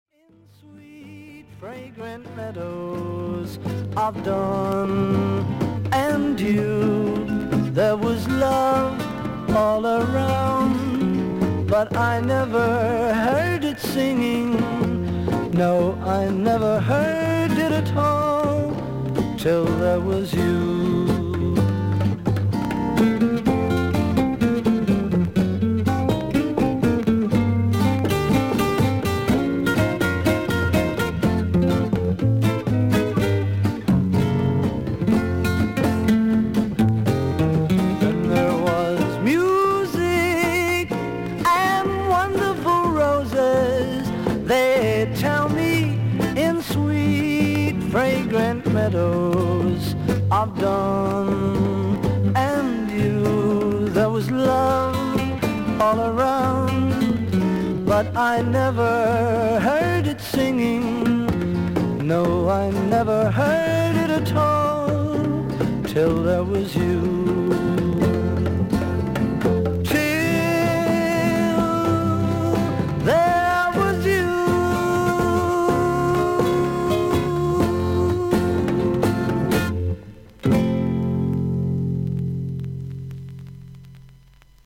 A6の中盤に5mmほどキズあり。少々軽い周回ノイズあり。
少々サーフィス・ノイズあり。クリアな音です。